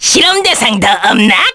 Lakrak-Vox_Victory_kr.wav